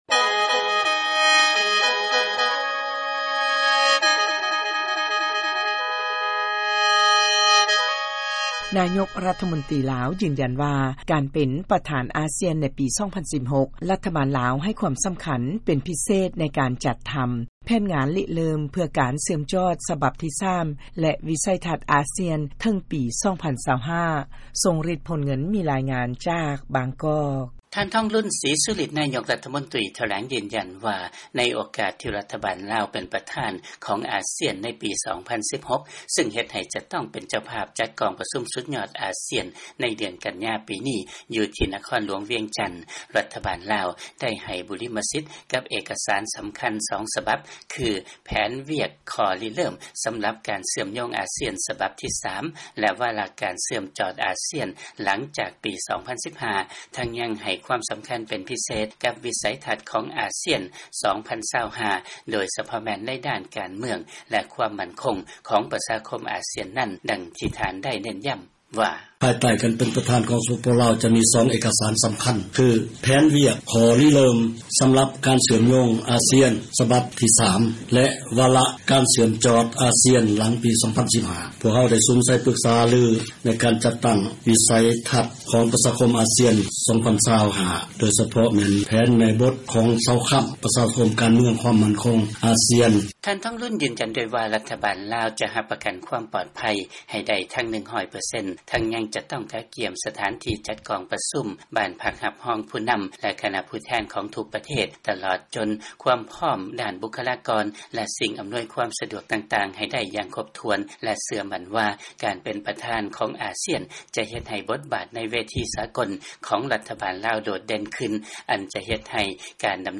ເຊີນຟັງ ລາຍງານ ລາວ ຈະເປັນປະທານ ເຈົ້າພາບ ຈັດ ກອງປະຊຸມ ສຸດຍອດອາຊຽນ ຄັ້ງທີ 28